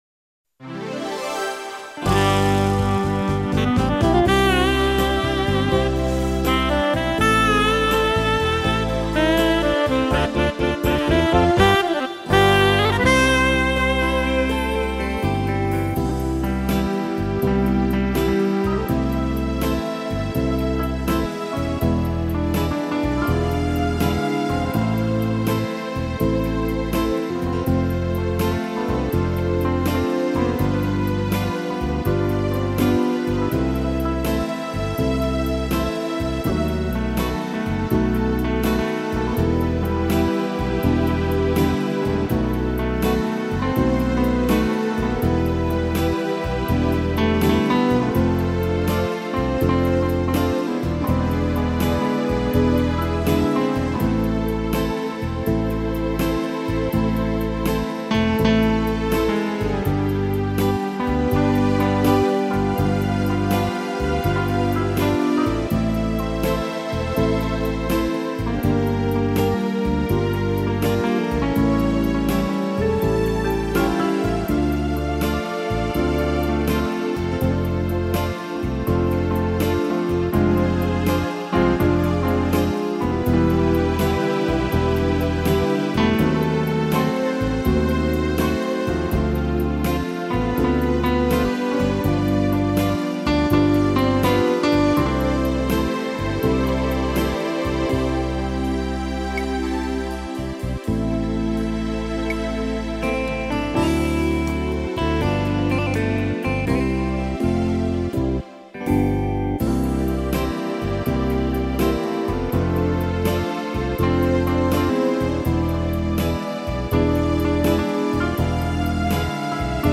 Soneto 015 (letra: Shakespeare - Trad. Thereza - música e arranjo: Rocha) (instrumental)